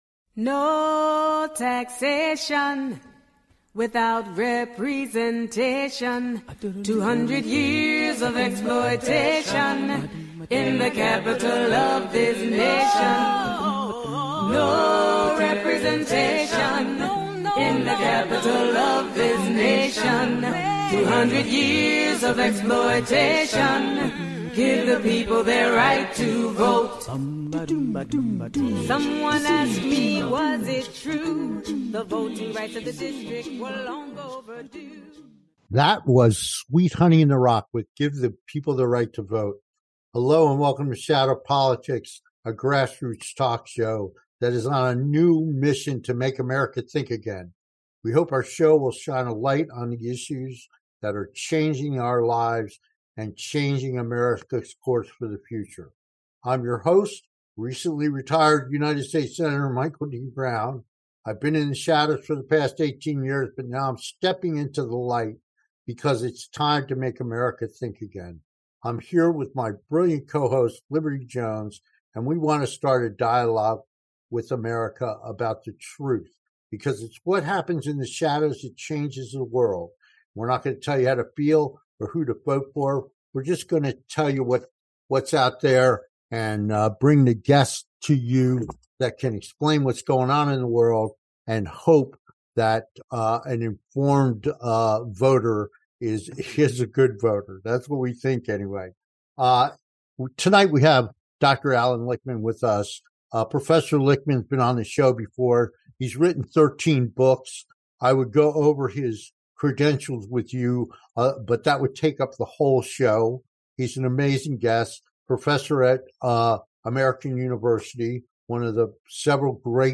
Guest, Dr Allan Lichtman - The history of conservatism in America and the current state of American Conservatism